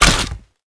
PixelPerfectionCE/assets/minecraft/sounds/mob/skeleton/hurt4.ogg at mc116
hurt4.ogg